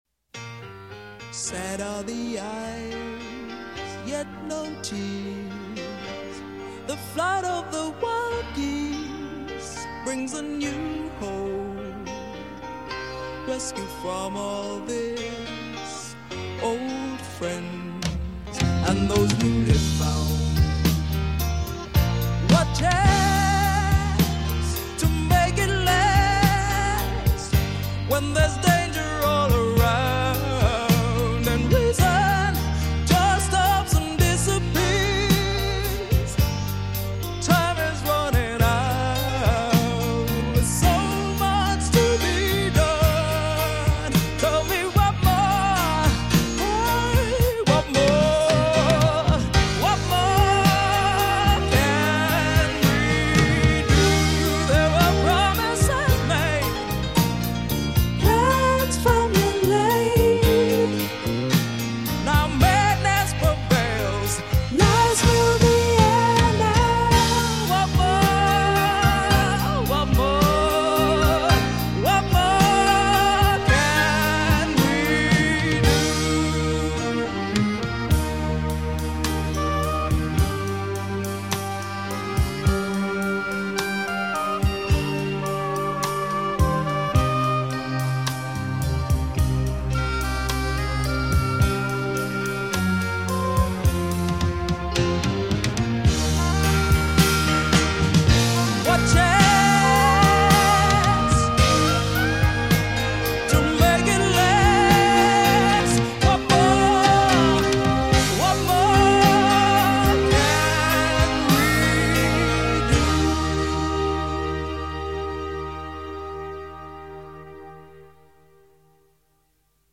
Format: Soundtrack